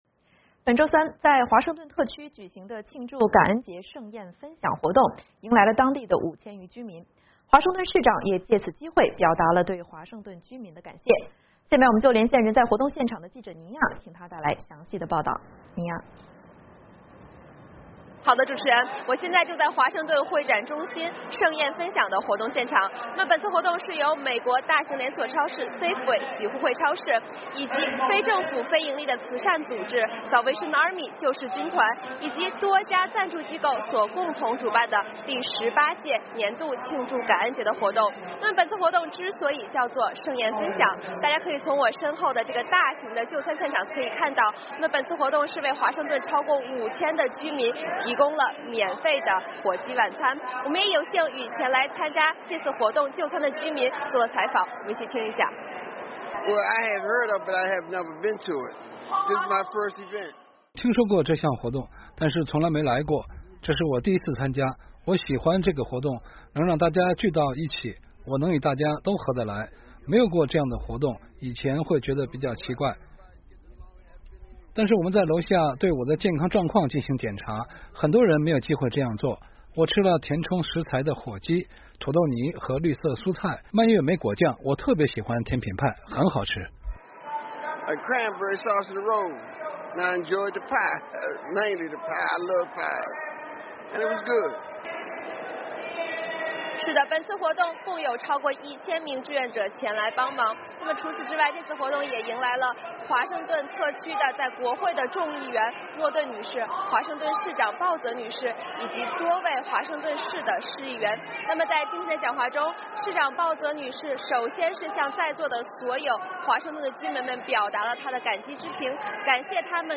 VOA连线：华盛顿感恩节前的“盛宴分享”